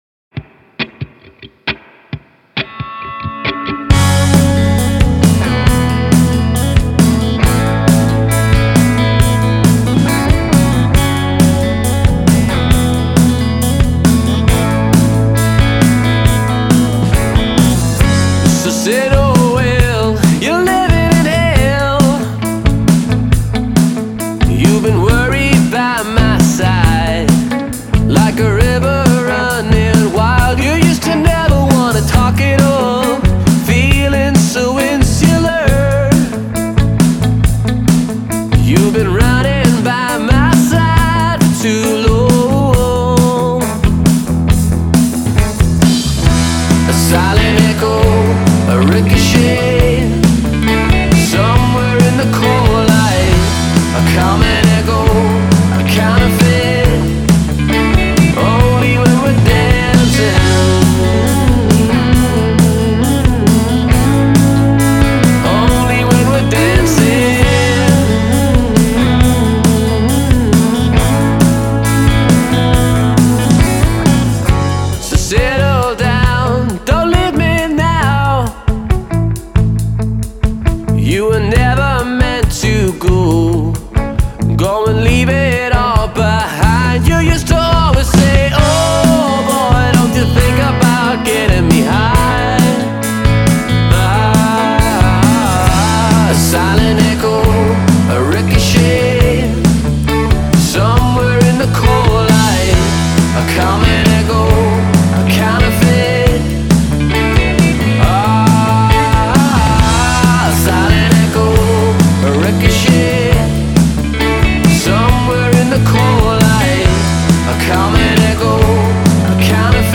• Genre: Indie Pop